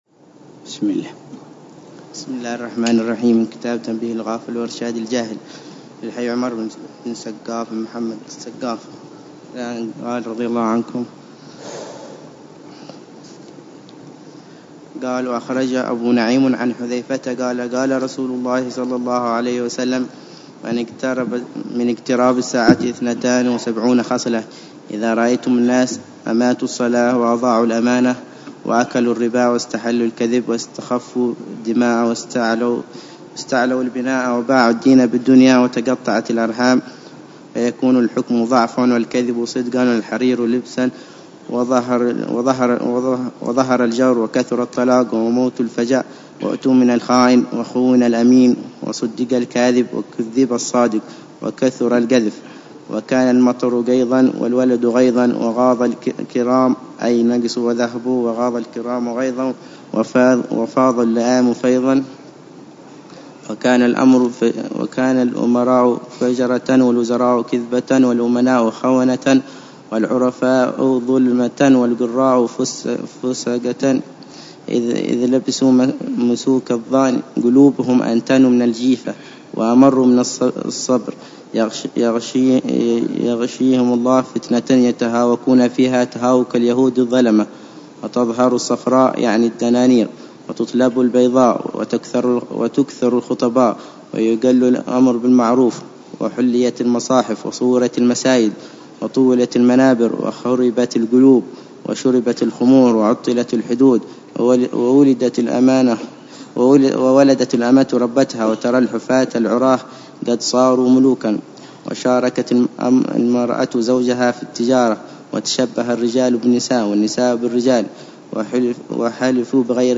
الدرس السادس عشر من شرح العلامة الحبيب عمر بن محمد بن حفيظ لكتاب : تنبيه الغافل وإرشاد الجاهل للإمام الحبيب : عمر بن سقاف بن محمد الصافي السقا